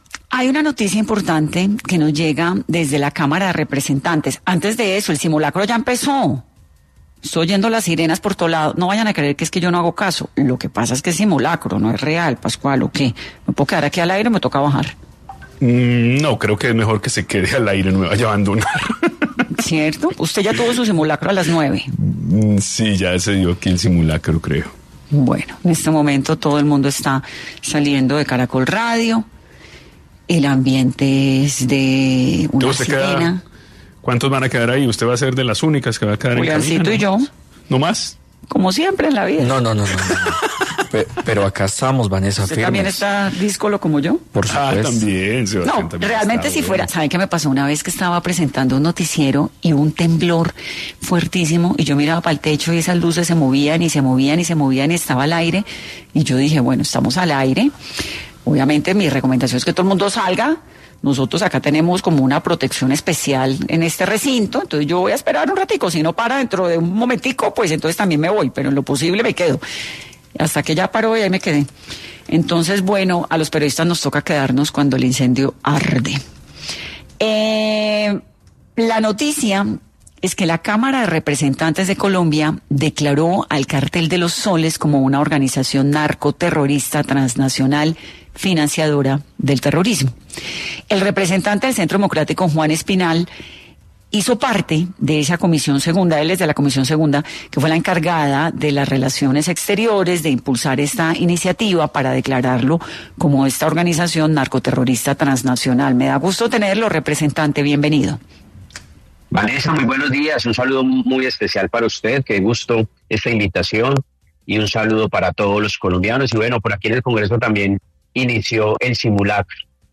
Juan Espinal, representante a la Cámara por el Centro Democrático, pasó por 10AM para hablar sobre el reconocimiento del Cartel de los Soles como organización narcoterrorista transnacional en el Congreso.